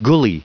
Prononciation du mot gully en anglais (fichier audio)
Prononciation du mot : gully